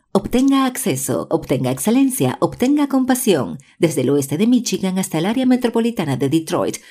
All voiceover projects are recorded and mixed by our in-house audio engineers to ensure high fidelity and natural sounding recordings.
Some of our Voice Over Samples
Spanish – Female voice
2023-Spanish-Female.wav